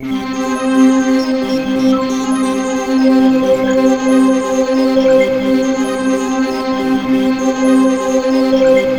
Index of /90_sSampleCDs/USB Soundscan vol.13 - Ethereal Atmosphere [AKAI] 1CD/Partition C/05-COMPLEX